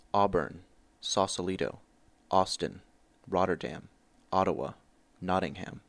I use Vowel 1 for “sauce” … but Vowel 2 for “Sausalito”.